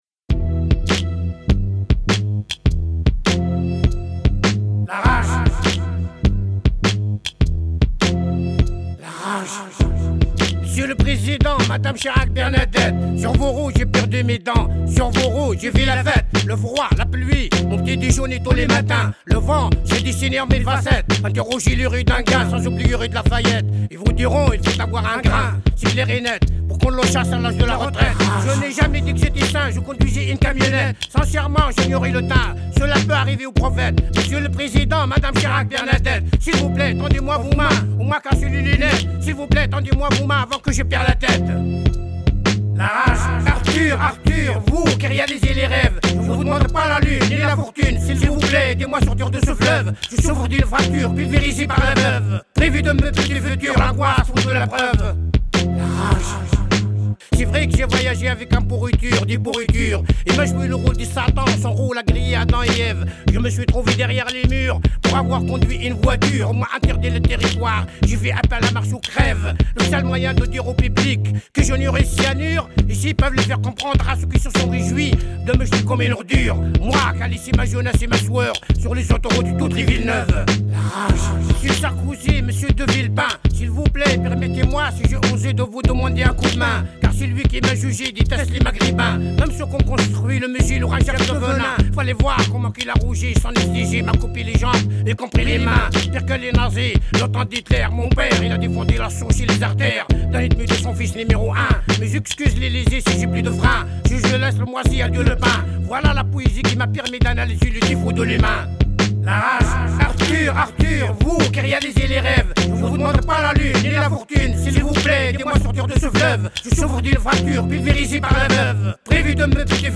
Émission